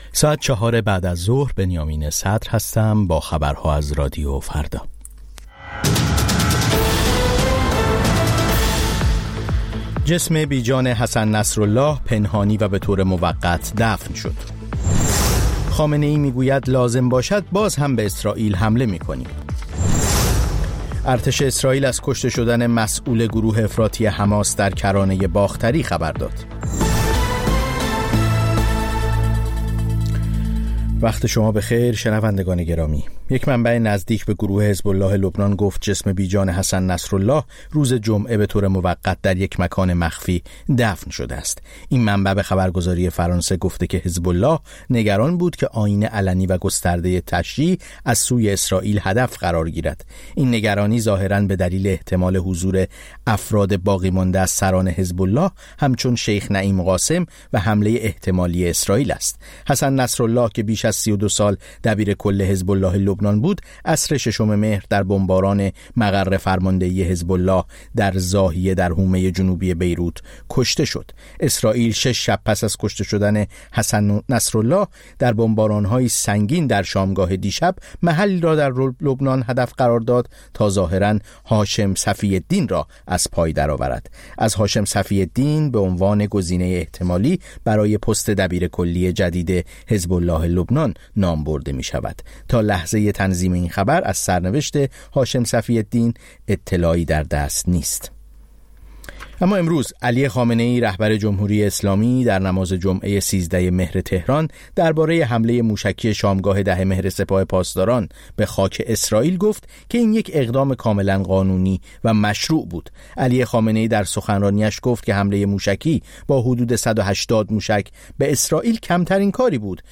سرخط خبرها ۱۶:۰۰